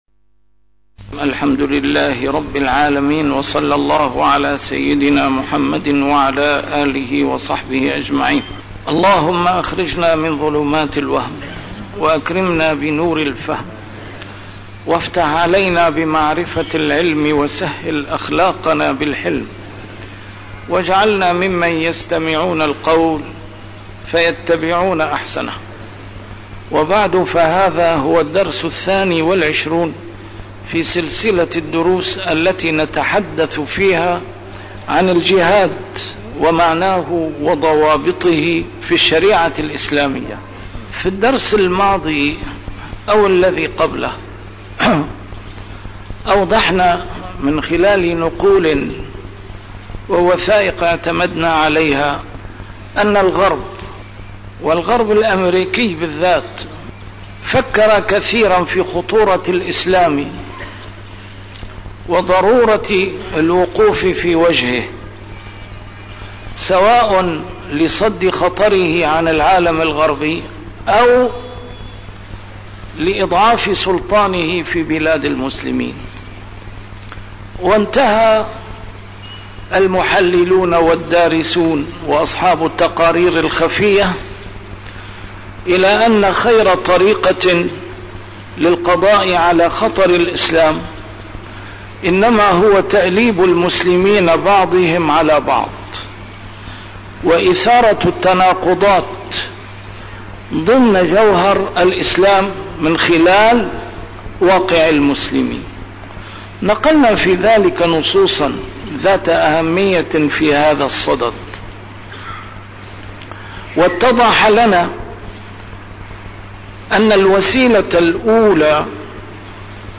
A MARTYR SCHOLAR: IMAM MUHAMMAD SAEED RAMADAN AL-BOUTI - الدروس العلمية - الجهاد في الإسلام - تسجيل قديم - الدرس الثاني والعشرون: سياسة التفكيك التي تتبعها الدول الكبرى في المنطقة